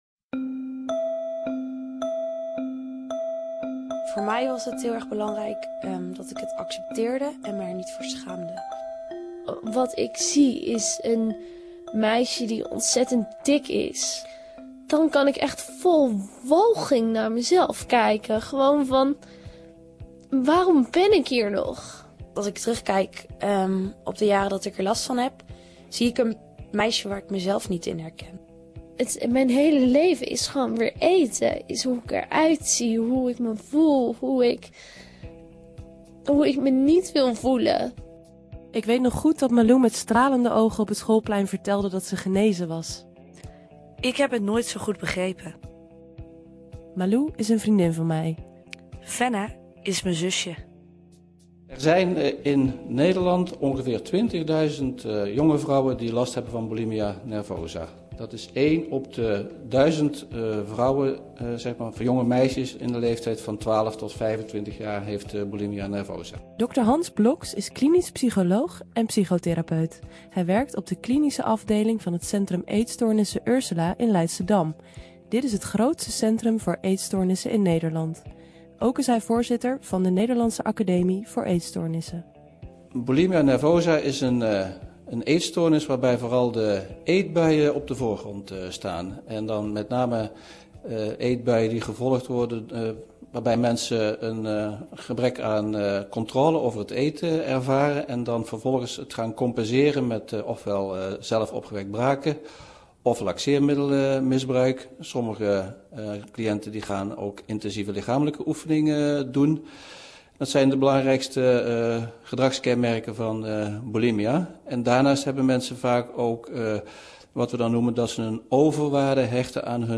Radiodocumentaire: over eten.